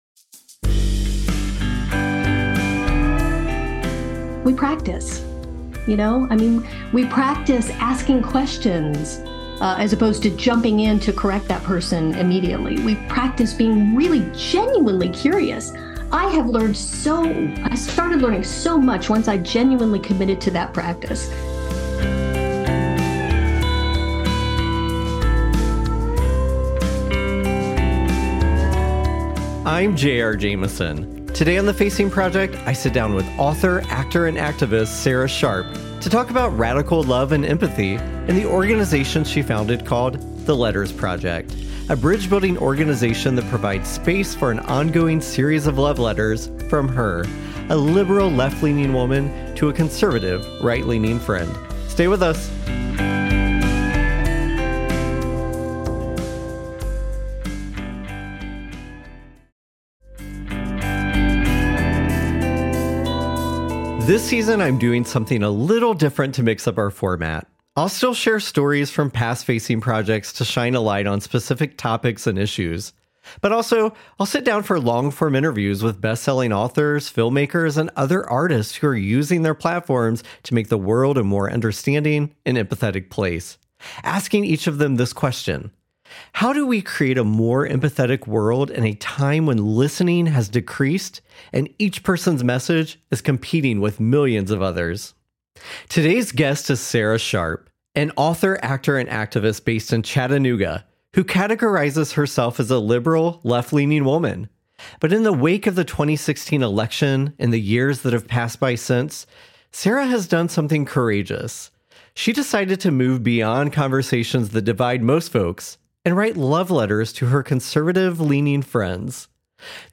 Live on 92.1 FM Muncie | 90.9 FM Marion | 91.1 FM Hagerstown / New Castle